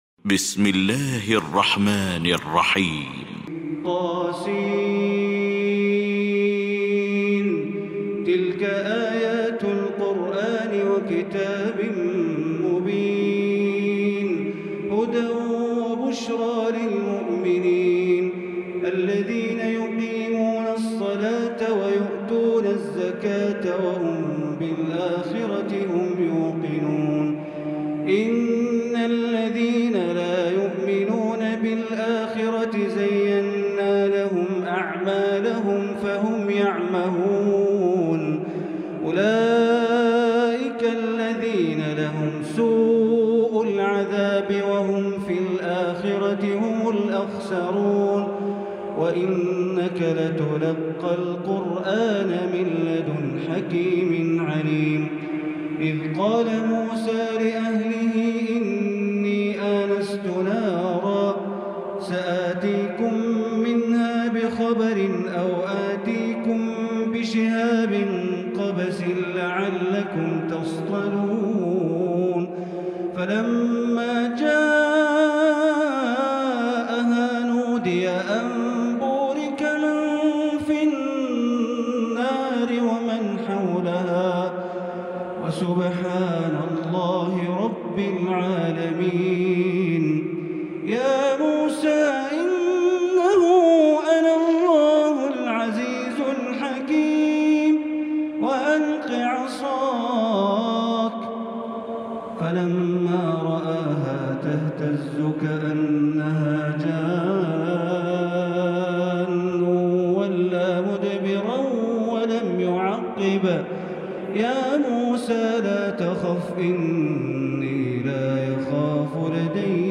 المكان: المسجد الحرام الشيخ: معالي الشيخ أ.د. بندر بليلة معالي الشيخ أ.د. بندر بليلة سعود الشريم النمل